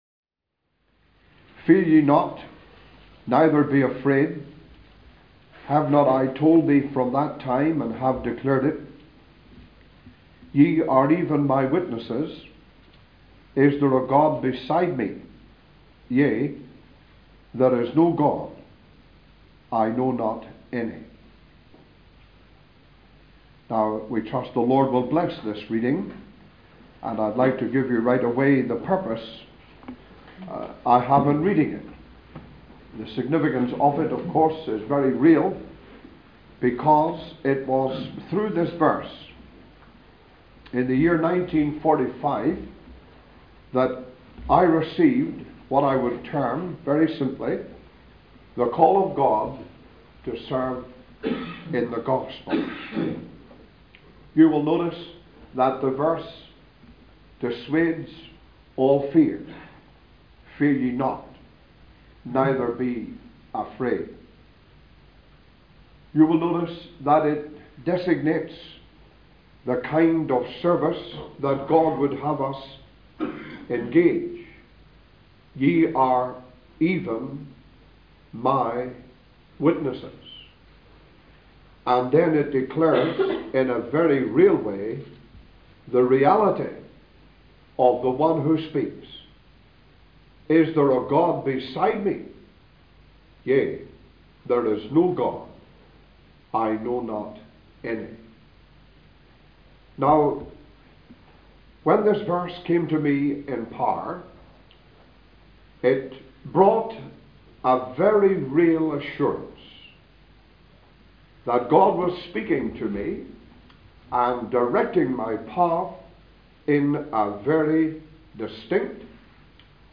(Recorded in Ballymena on 21st Dec 1996) (Photo: Kuala Lumpur, Malaysia)
Historical Testimonies